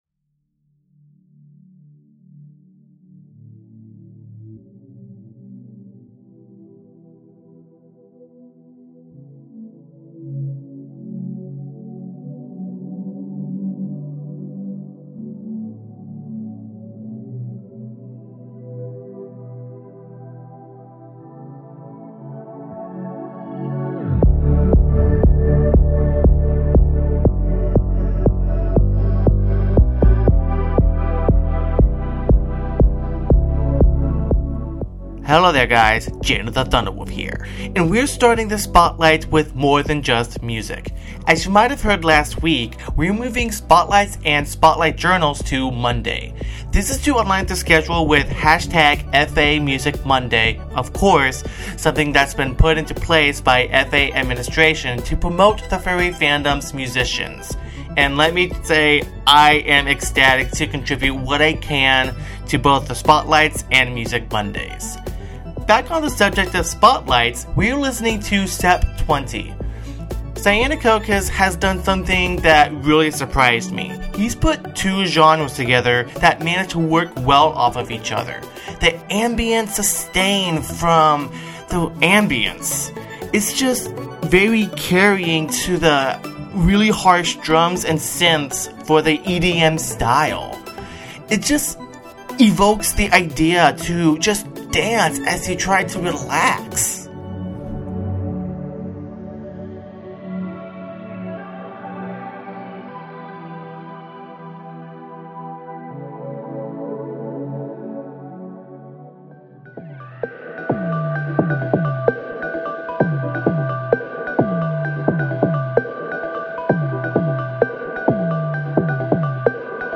(Ambient EDM)
(Dubstep)
(Synthwave)